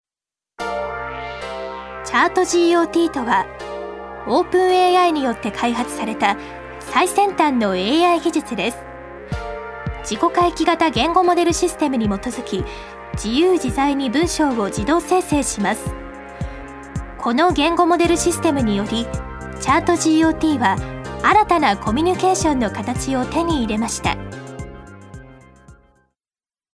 女性タレント
1. ナレーション１